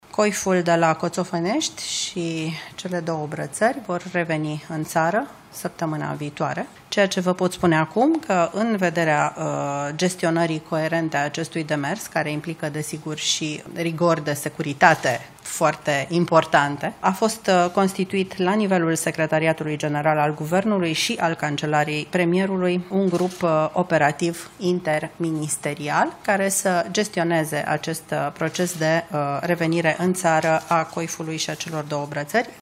Ioana Ene Dogioiu, purtătorul de cuvânt al Guvernului: „Coiful de la Coțofenești și cele două brățări vor reveni în țară săptămâna viitoare”